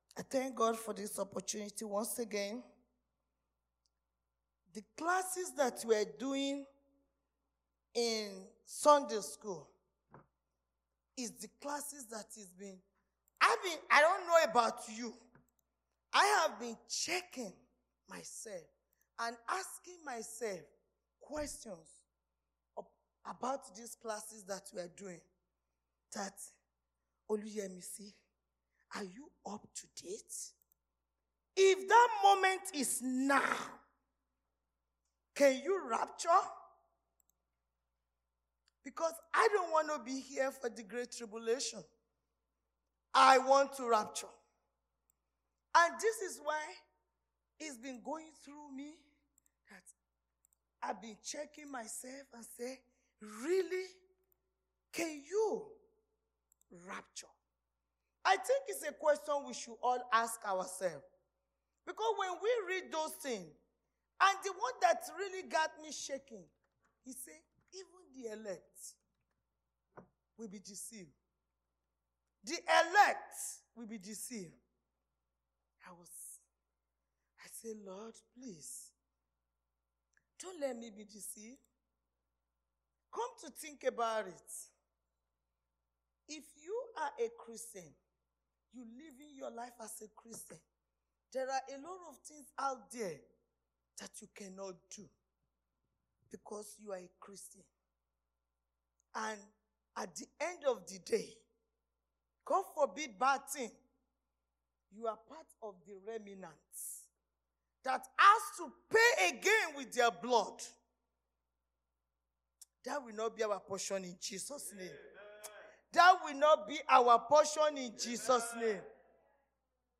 Sunday Sermon- The Grace To Live In Him & The Power To Do His Will.
Service Type: Sunday Church Service